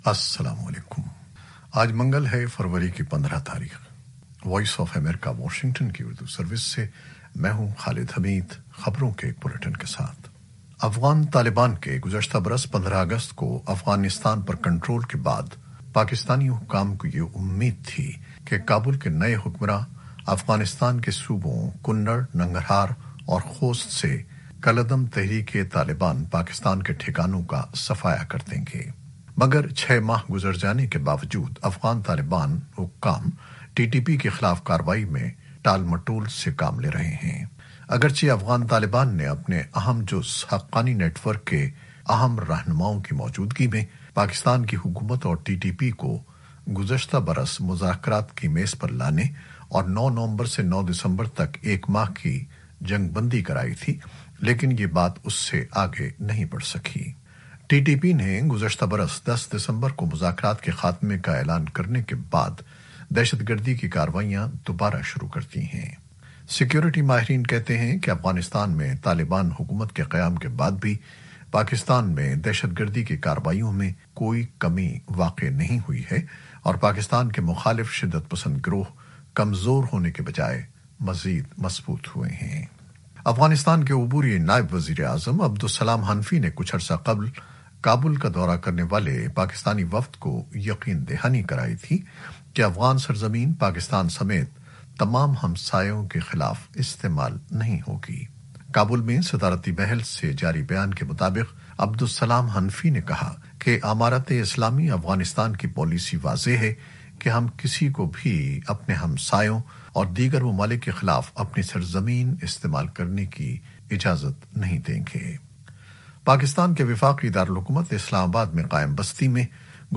نیوز بلیٹن 2021-15-02